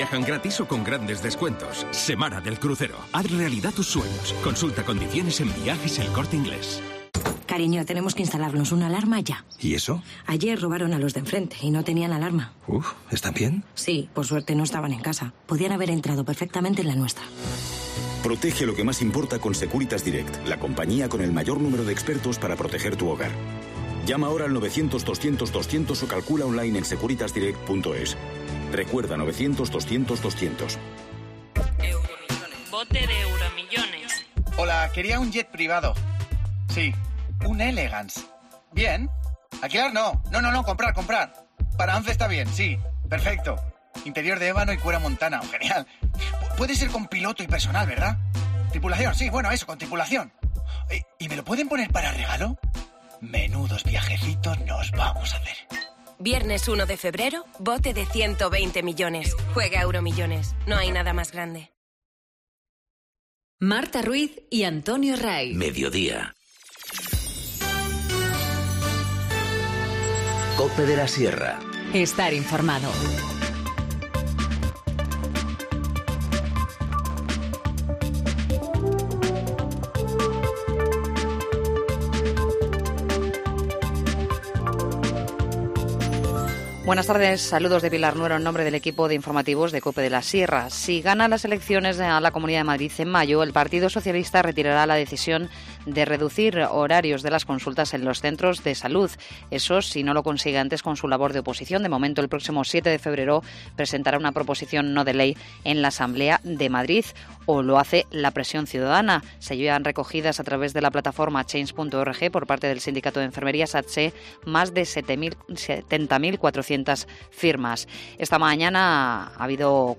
Informativo Mediodía 31 enero- 14:20h